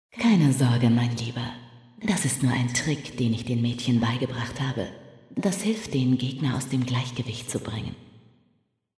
Listen to the telepathically transmitted advice of the enchanting Emma Frost, smile at the likeably rough remarks of Wolverine and shiver when you hear the sinister voice of their enemy Apocalypse - all in German.